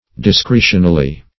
Search Result for " discretionally" : The Collaborative International Dictionary of English v.0.48: Discretionally \Dis*cre"tion*al*ly\, Discretionarily \Dis*cre"tion*a*ri*ly\, adv. At discretion; according to one's discretion or judgment.